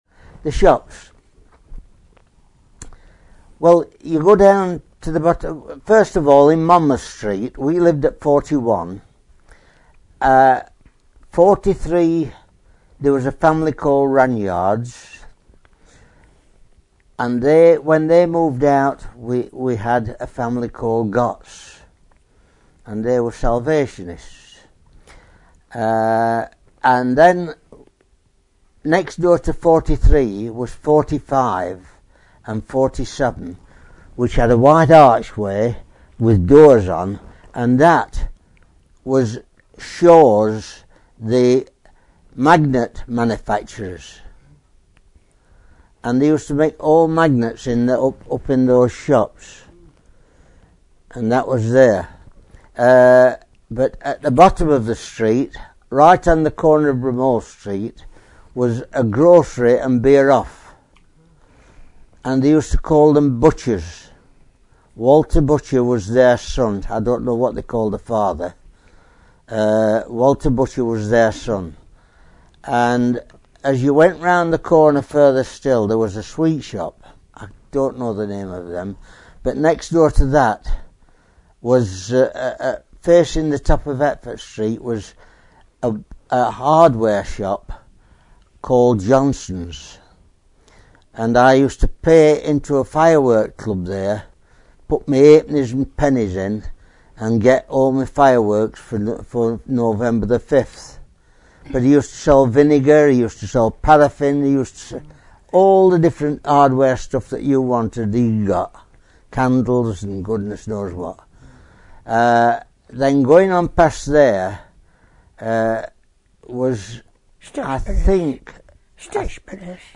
Past residents discuss the numerous local shops and businesses of yesteryear
In these audio interviews with folk who grew up locally, a real sense of the bustle of the area comes through, and of what a close-knit community it was – where everyone seemed to know everyone else – and their business!